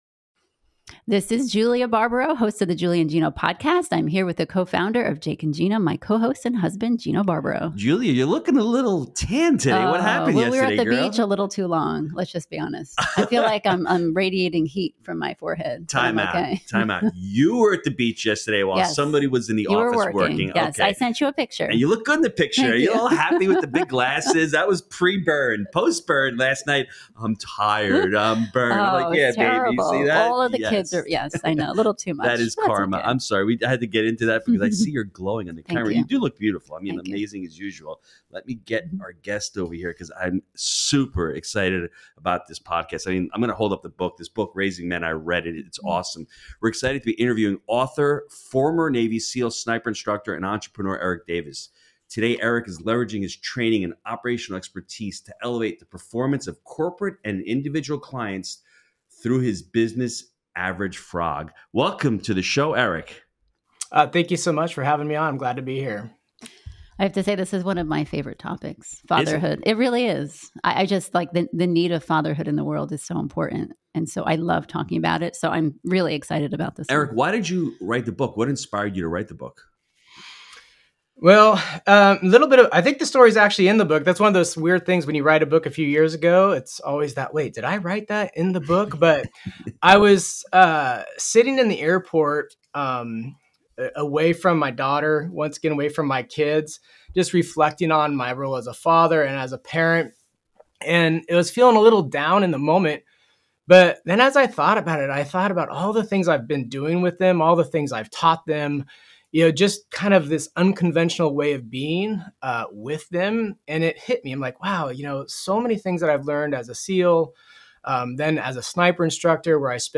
The conversation also touches on the importance of consequences, behavior science, and understanding each child's unique needs.